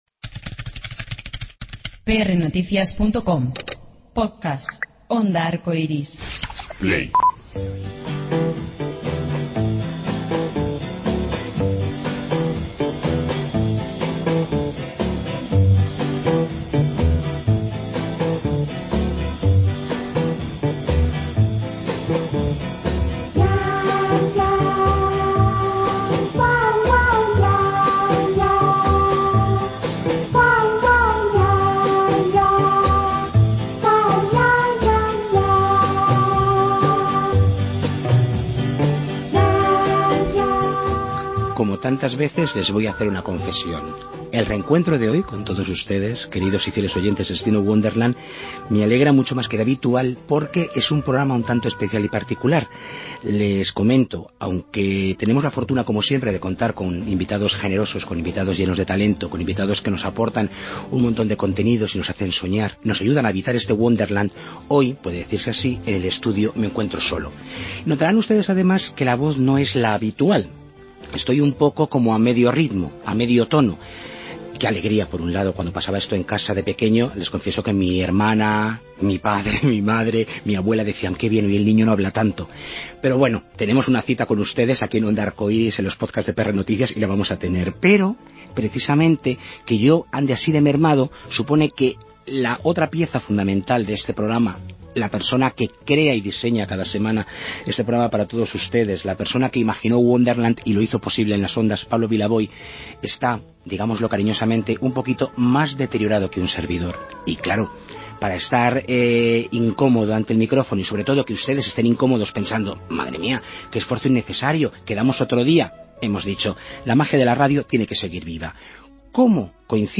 De ello hablamos con él.